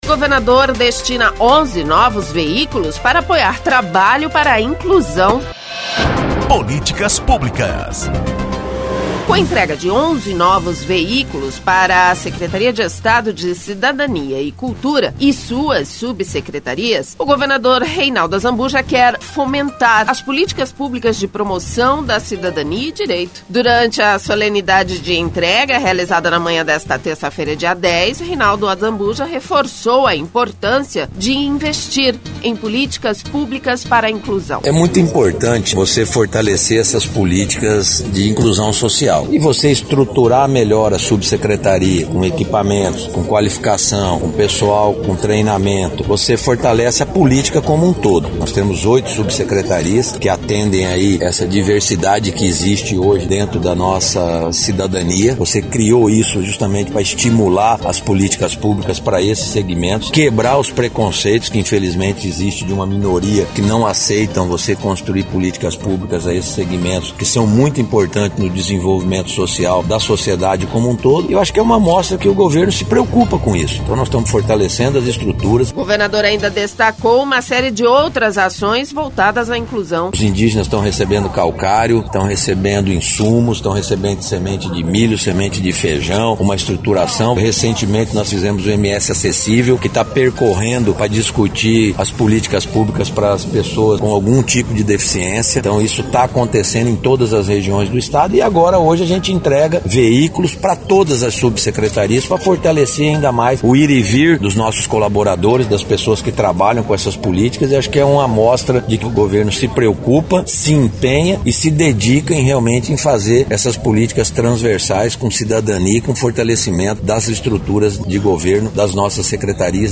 Durante a solenidade de entrega, realizada na manhã desta terça-feira, dia 10, Reinaldo reforçou a importância de investir em políticas públicas de inclusão.